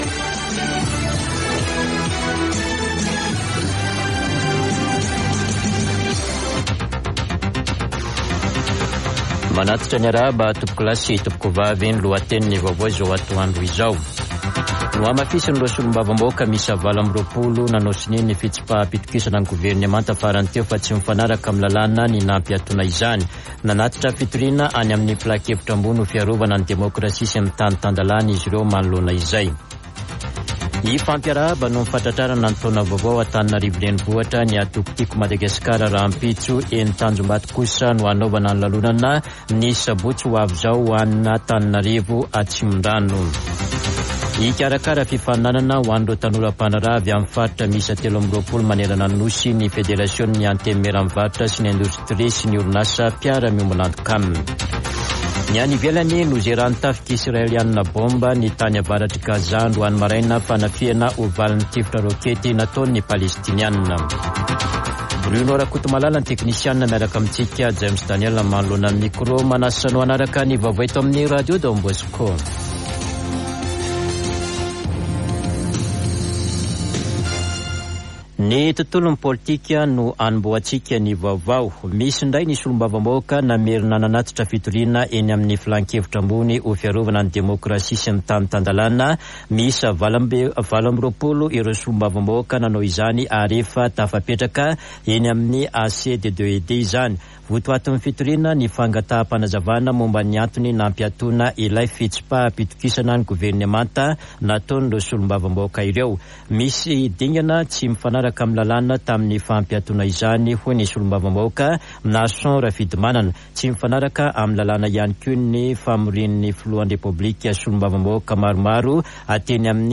[Vaovao antoandro] Alakamisy 23 febroary 2023